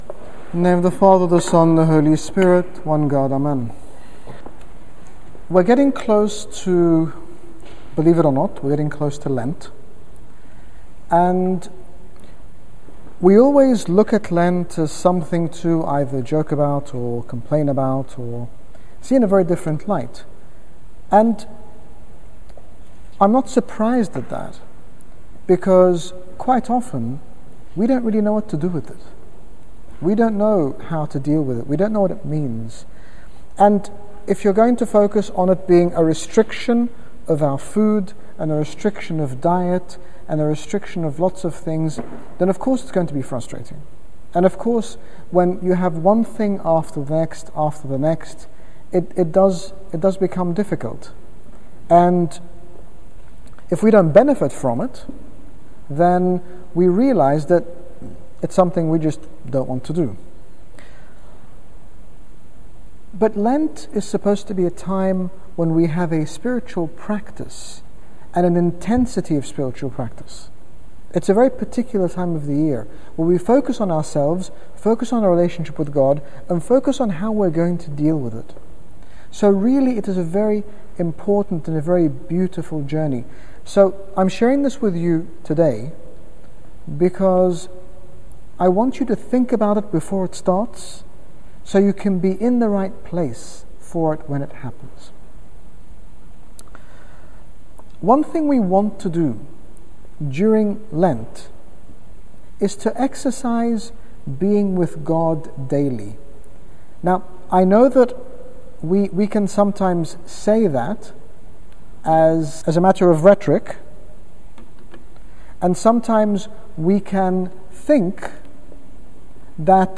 In this talk His Grace Bishop Angaelos speaks about Lent as a time to invest in our relationship with God, highlighting different ways we can deepen that relationship with and love for Him.